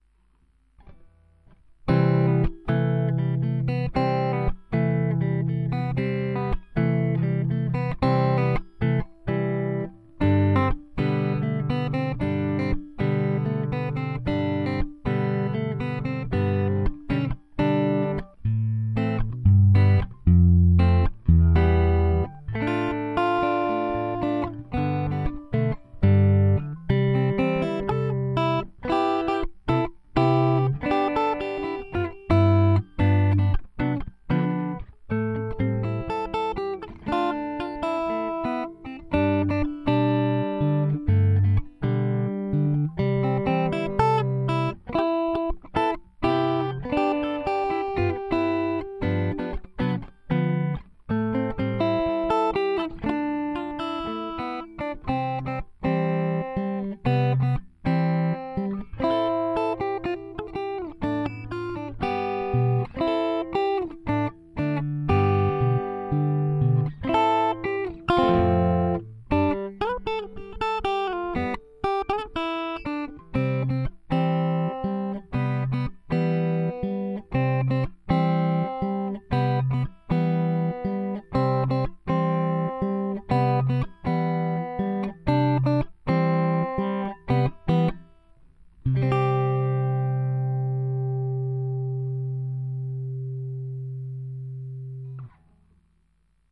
国内外問わず、大人から子供まで大人気のアニメソングをソロギターで楽しもう！